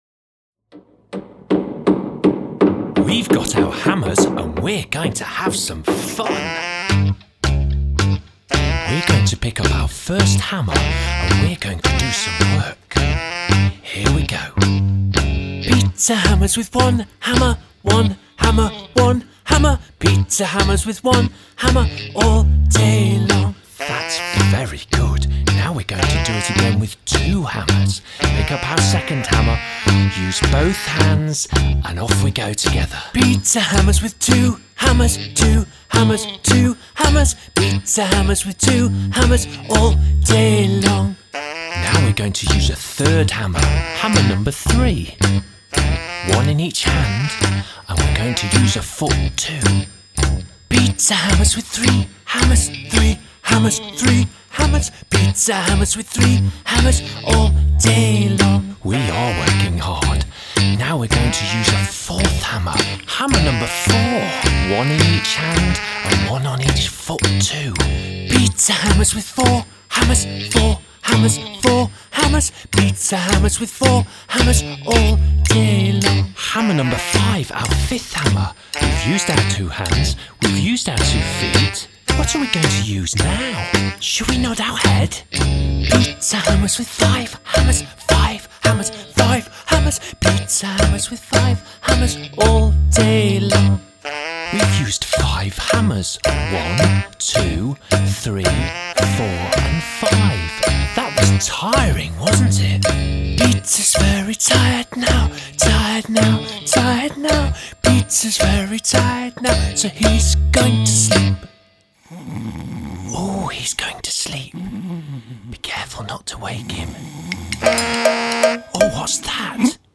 traditional action song